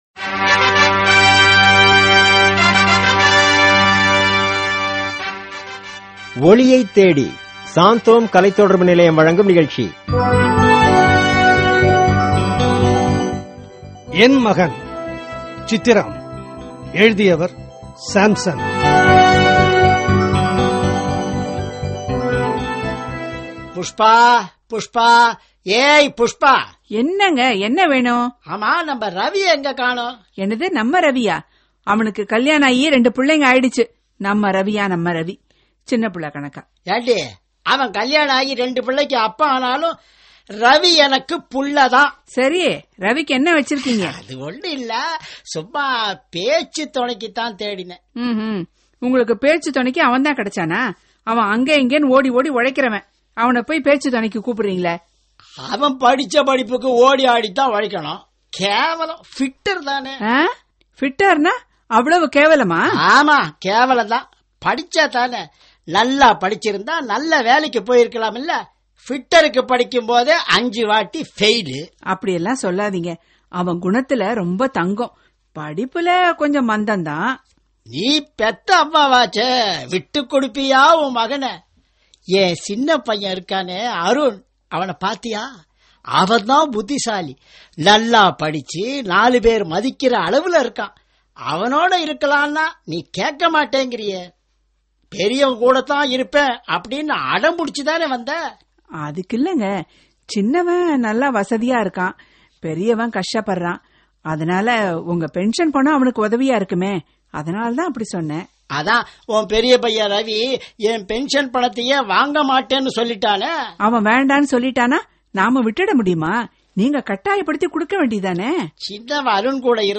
Directory Listing of mp3files/Tamil/Dramas/Oliyai Thaedi ( Short Drama )/ (Tamil Archive)